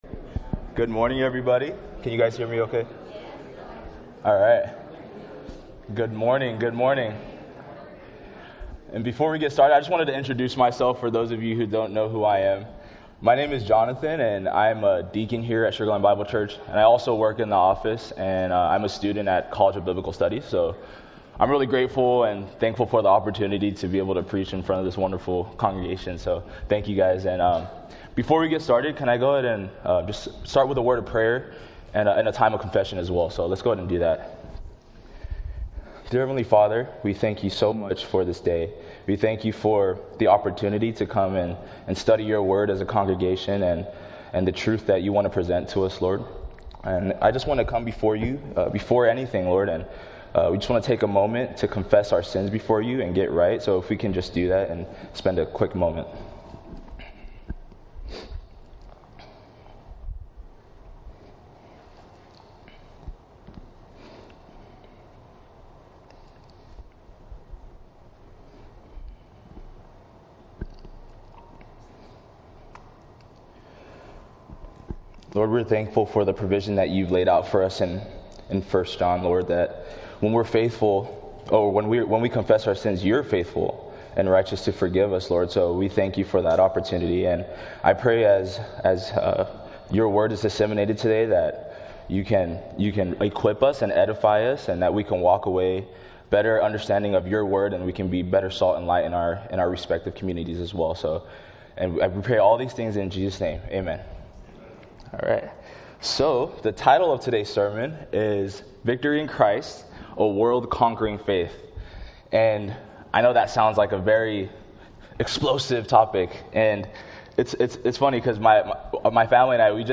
2025 • Guest Speaker Listen Now Download Audio Slides Previous Sermon What State Is Israel In?